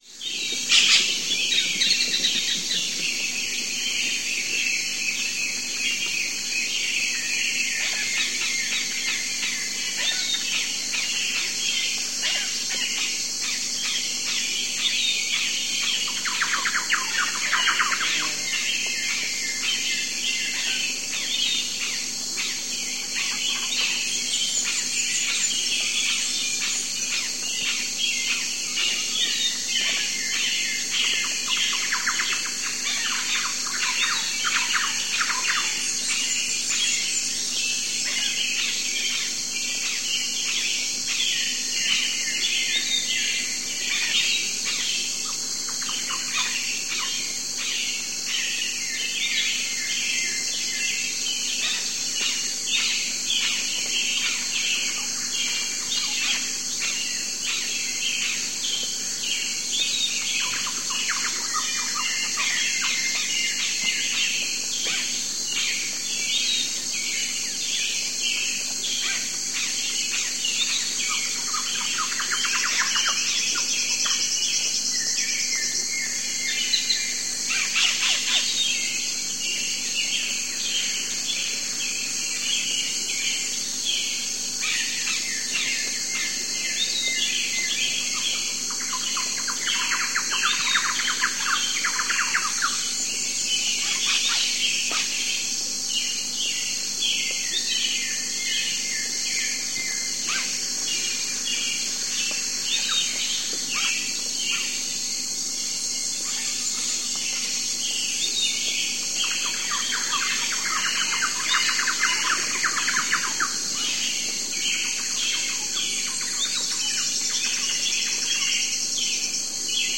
Звуки тропического леса
Тропические пернатые и крылатые насекомые